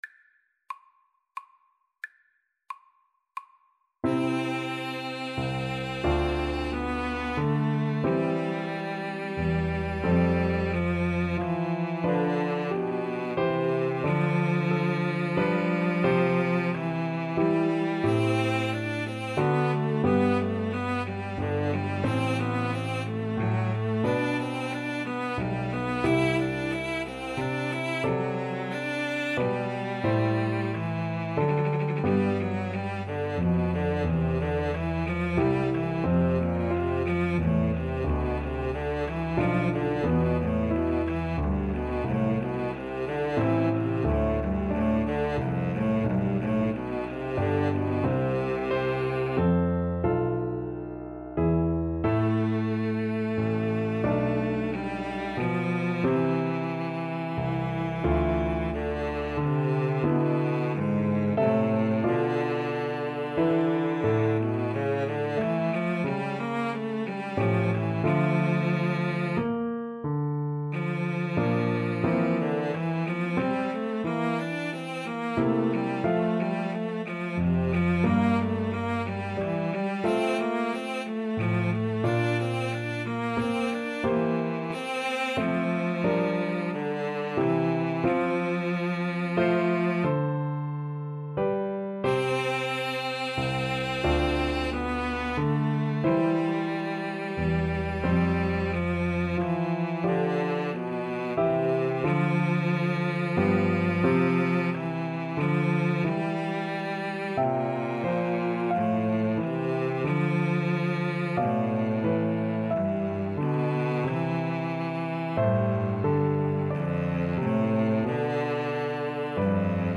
Largo ma non tanto ( = c. 90)
Classical (View more Classical Cello Duet Music)